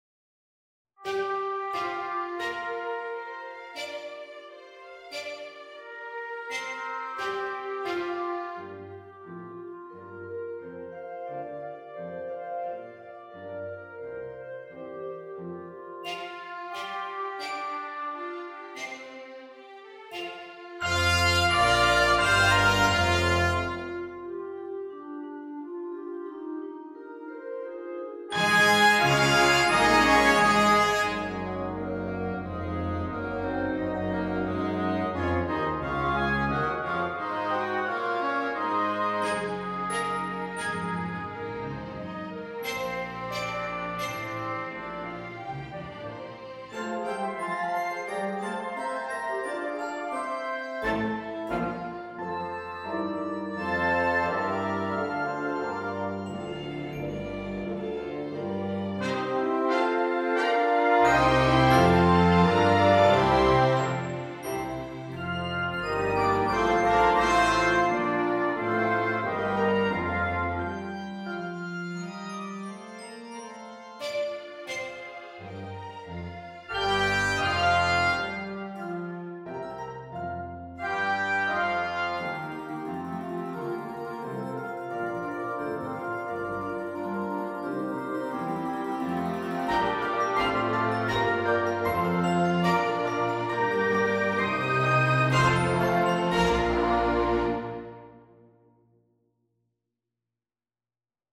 Large Ensemble:
a sort of miniature orchestra